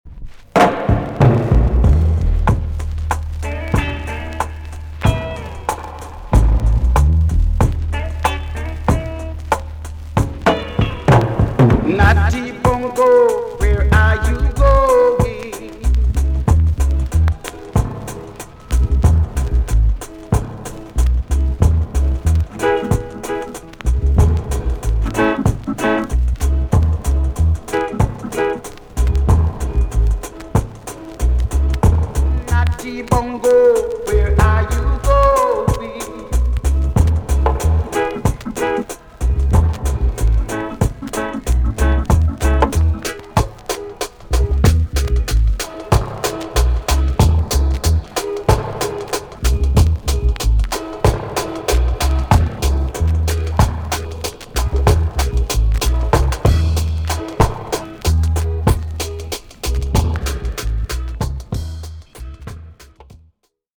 TOP >REGGAE & ROOTS
B.SIDE Version
EX- 音はキレイです。